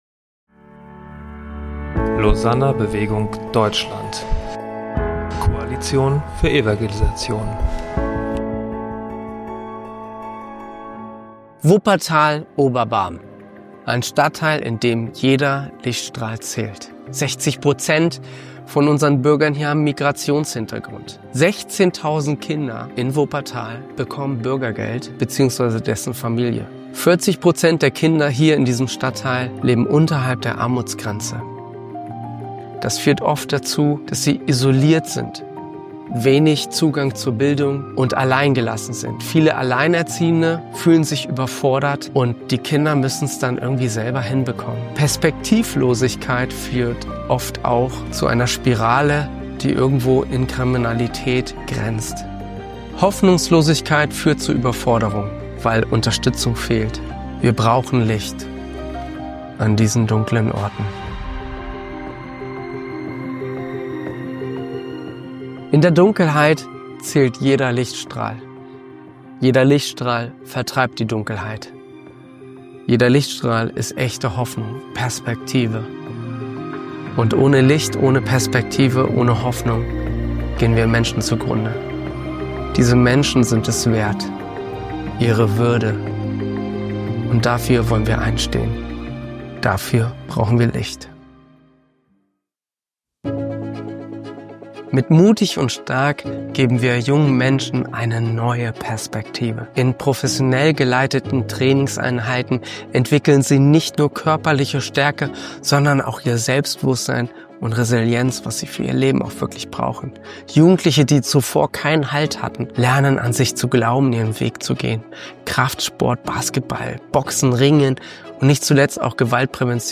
Beim Lausanner Forum in Marburg
am 20.01.2026 einen leidenschaftlichen, ehrlichen und herausfordernden Vortrag über eine Kirche, die mitten im Leben der Menschen steht.